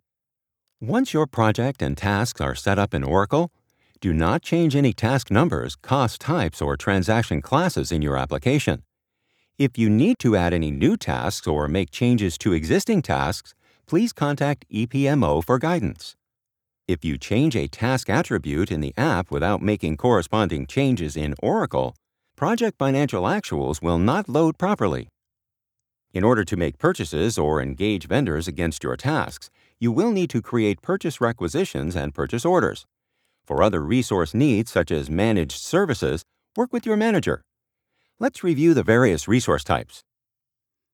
Mature Adult, Adult
Has Own Studio
standard us